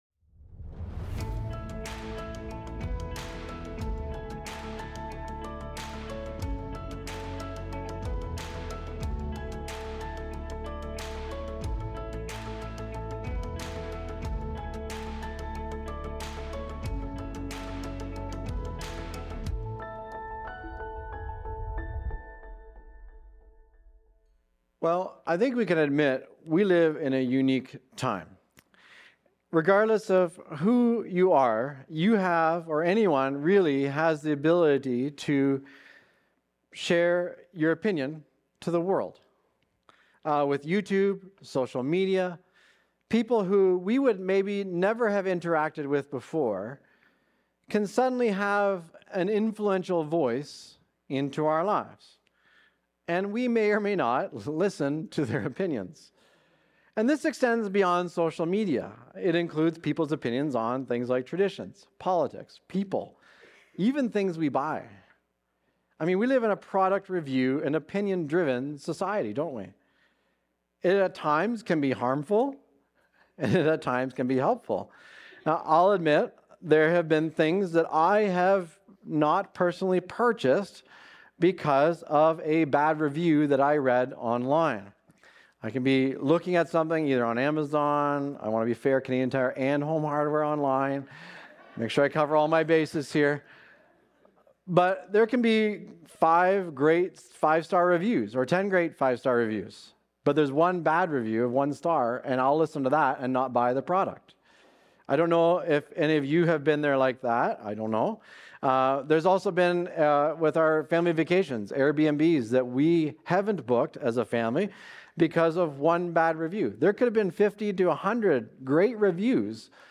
Recorded Sunday, February 22, 2026, at Trentside Fenelon Falls.